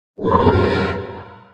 dc0f4c9042 Divergent / mods / Soundscape Overhaul / gamedata / sounds / monsters / poltergeist / attack_7.ogg 10 KiB (Stored with Git LFS) Raw History
attack_7.ogg